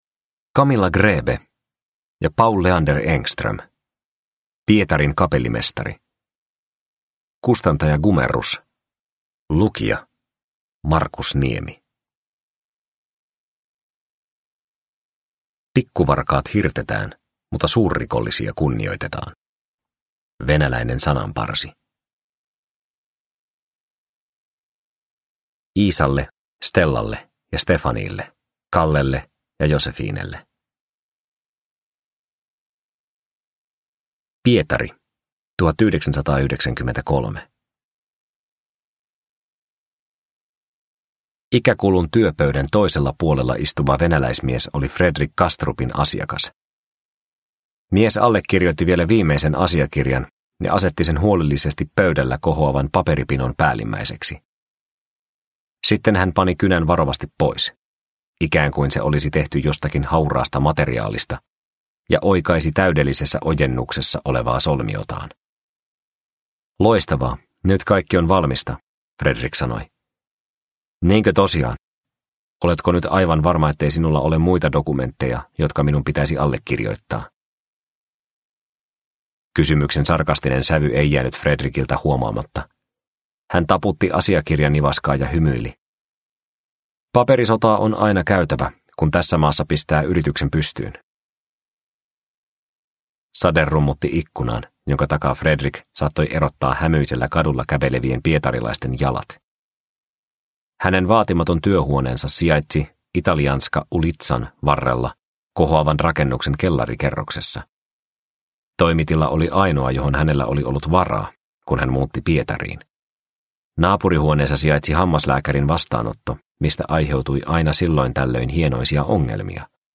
Pietarin kapellimestari – Ljudbok – Laddas ner